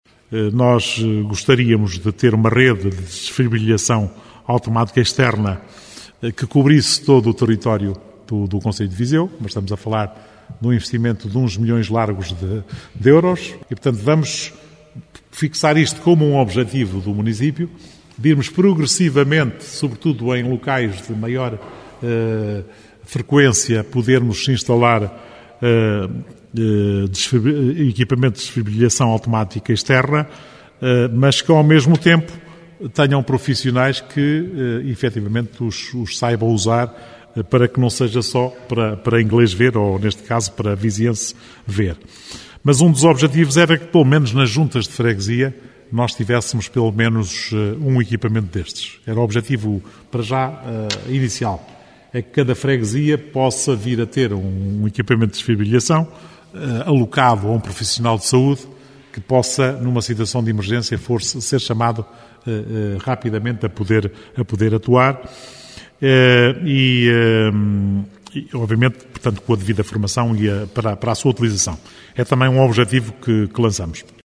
A Câmara de Viseu quer também equipar todas as Juntas de Freguesia com desfibriladores e um profissional com formação que saiba operar este tipo de equipamento, referiu Almeida Henriques.